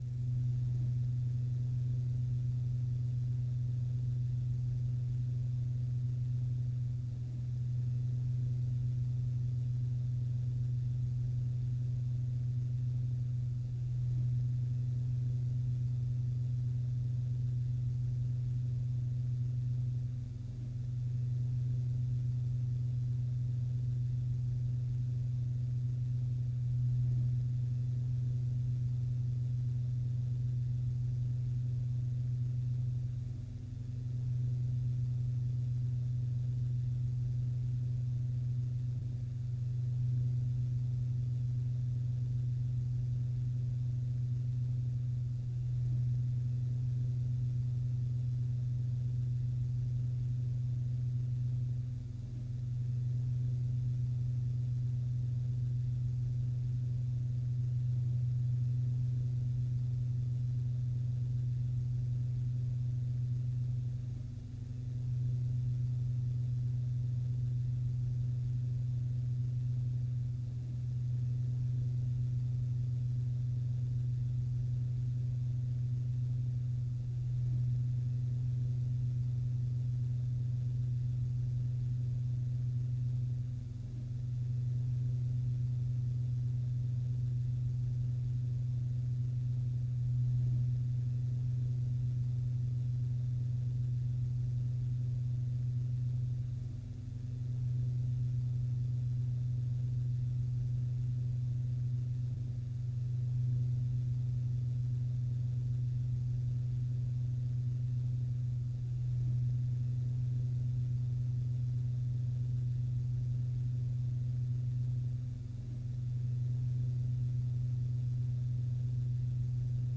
飞机内部，乘客安全带信号
描述：在飞机内部，低发动机噪音和乘客安全带信号
Tag: 信号 内部 飞机 里面 安全带 乘客